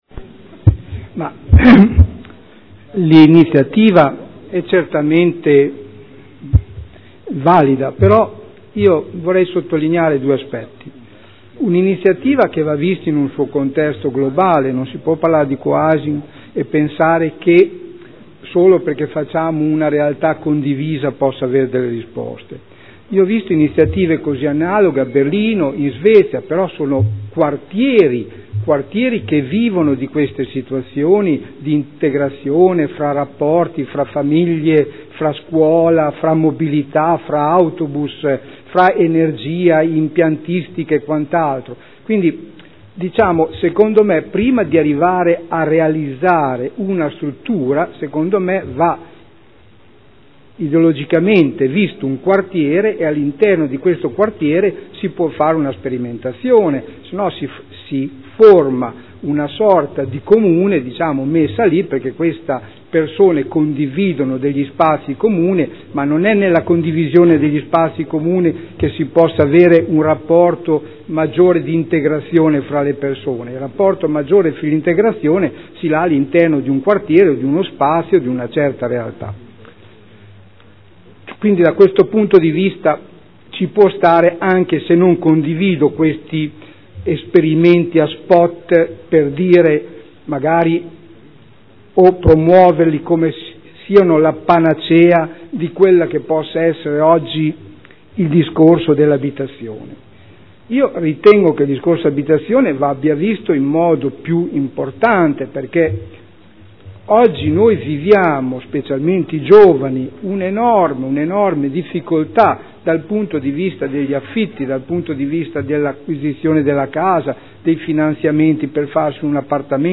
Seduta del 13/05/2013 Dibattito.